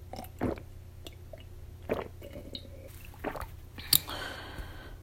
B_drink.ogg